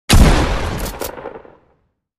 shot.mp3